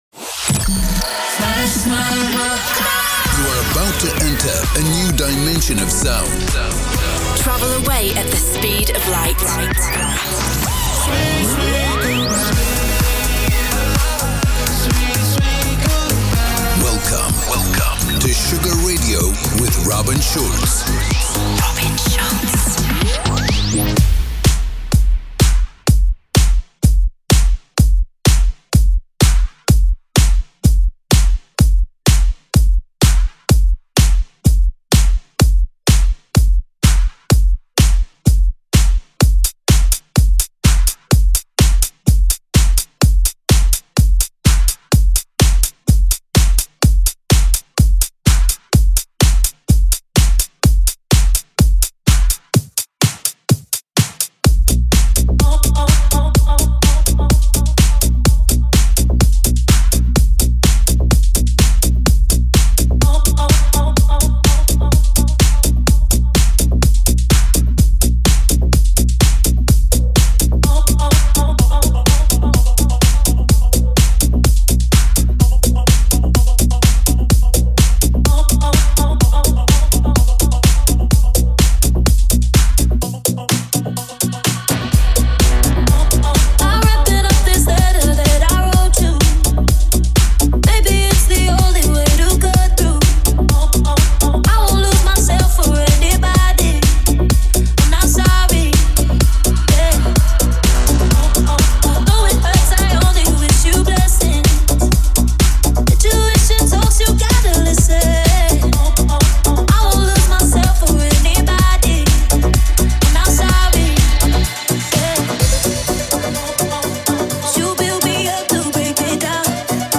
music DJ Mix in MP3 format
Electro Pop